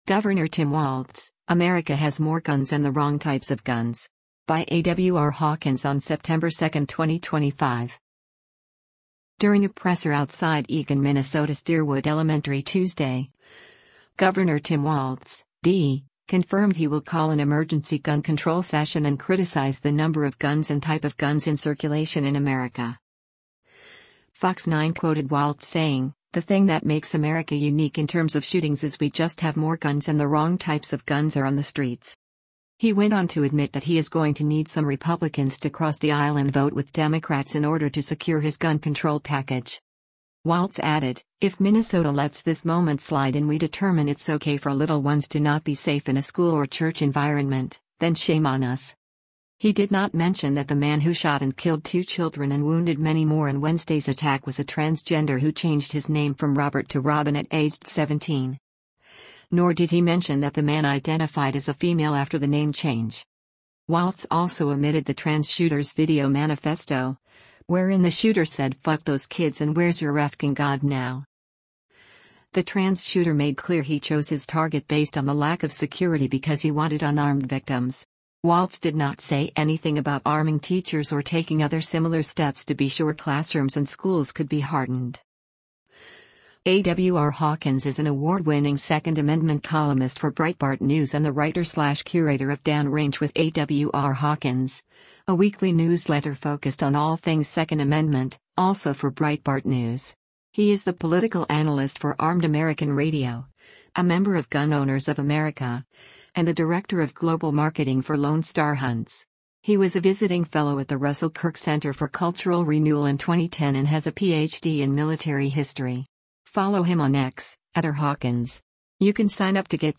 During a presser outside Eagan, Minnesota’s Deerwood Elementary Tuesday, Gov. Tim Walz (D) confirmed he will call an emergency gun control session and criticized the number of guns and “type of guns” in circulation in America.